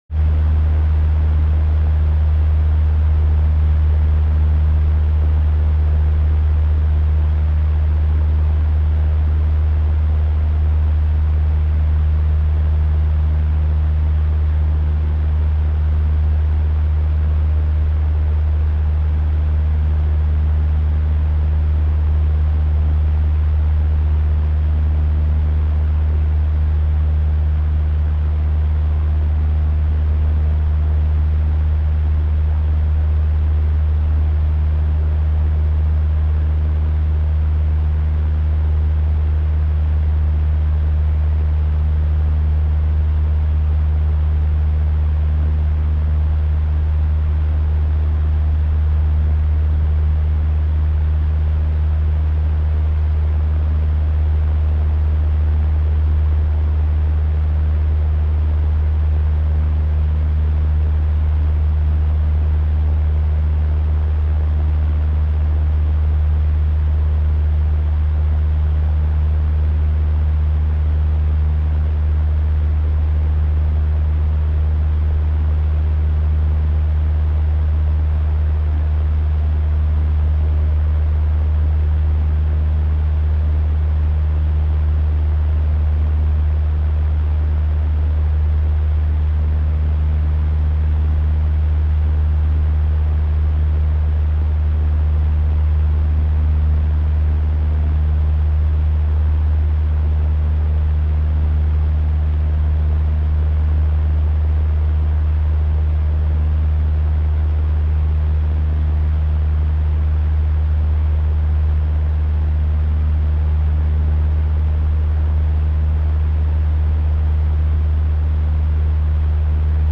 Best ever relaxing (fan sounds sound effects free download